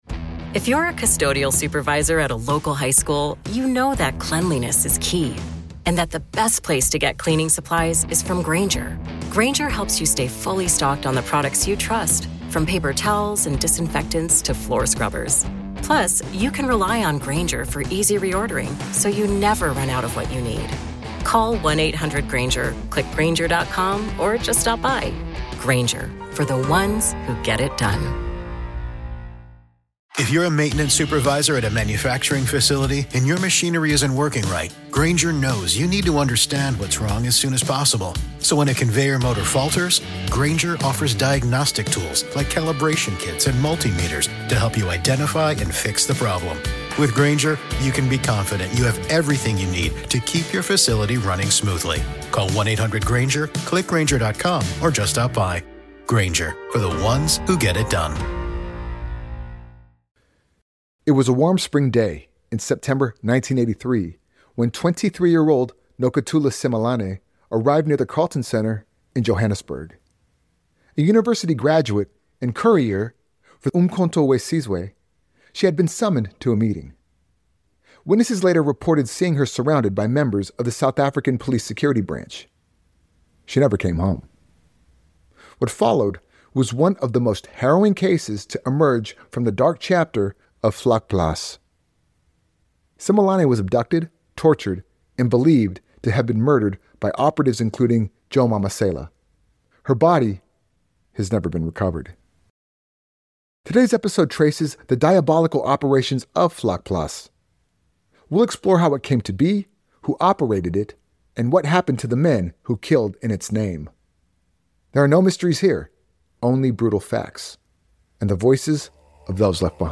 This True Crime Podcast was researched using open-source or archive materials.